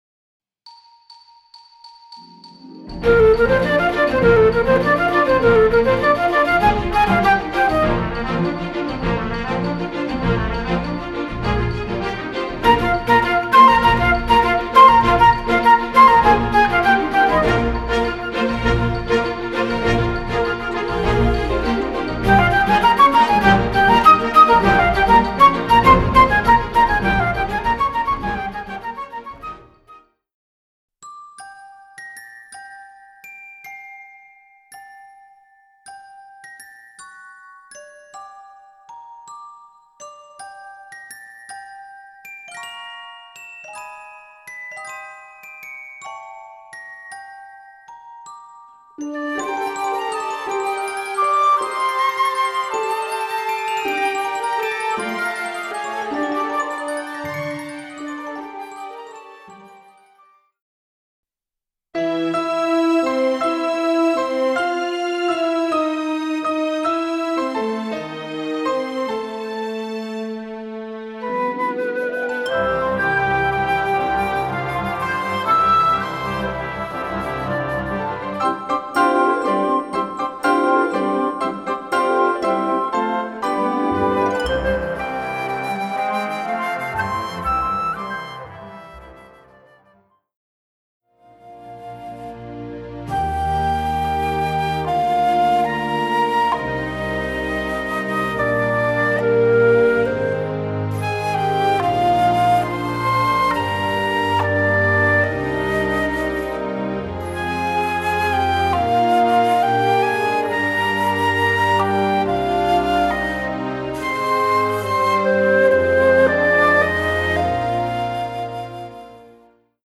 Voicing: Flute w/ Audio